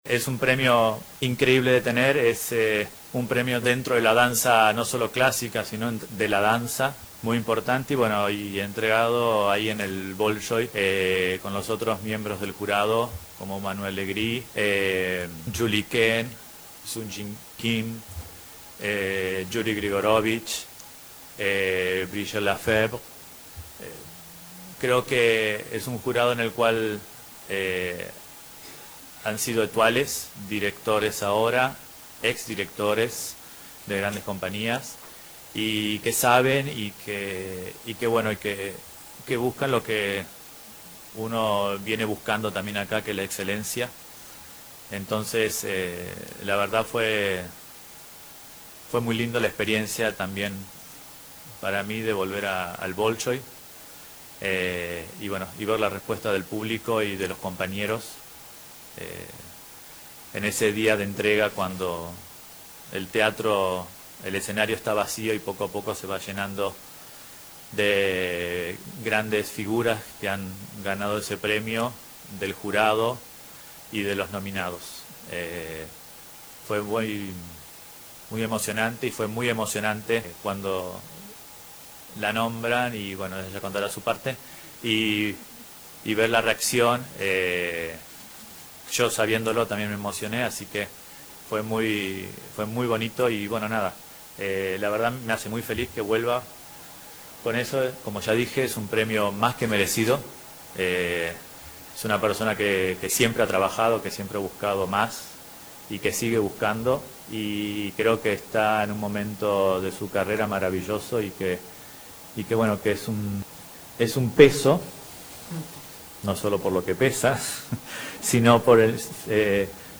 El presidente en ejercicio de la Presidencia, Raúl Sendic, recibió este jueves a la bailarina uruguaya premiada en Moscú, María Noel Riccetto, junto al director del Ballet Nacional del Sodre, Julio Bocca. Al término del encuentro, en conferencia de prensa, Bocca resaltó el trabajo de Riccetto y el premio obtenido y dijo que pasará a la historia dentro de las grandes figuras de la danza.